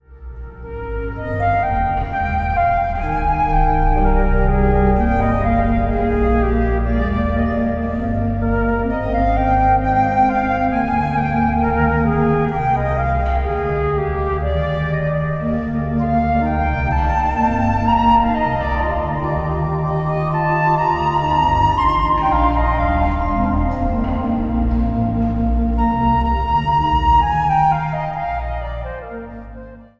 met medewerking van dwarsfluit, orgel en synthesizer
Instrumentaal | Dwarsfluit
Instrumentaal | Panfluit
Instrumentaal | Synthesizer